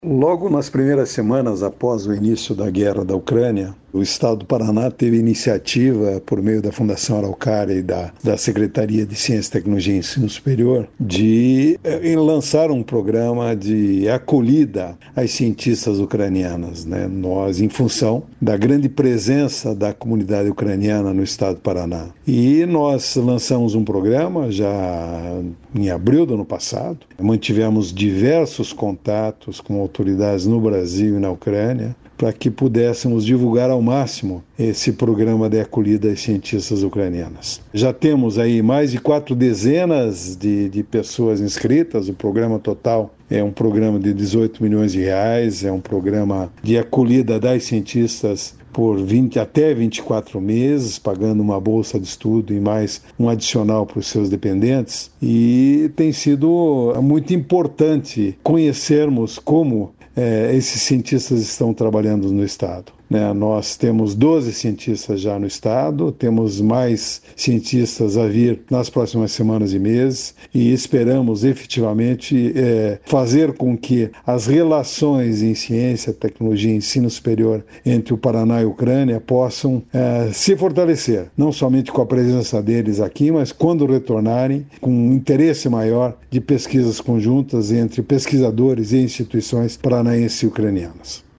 Sonora do presidente da Fundação Araucária, Ramiro Wahrhaftig, sobre o Programa de Acolhida aos Cientistas Ucranianos